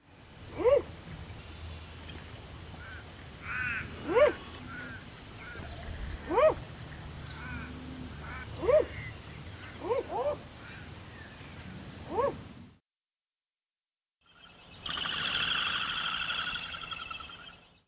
Whistling wings
They soon departed with their characteristic clatter of whistling wing-beats which can be heard in the audio below, together with their “whoop” call. The loud wing noise is apparently created by the particular alignment of a single flight feather.
crested-pigeon.mp3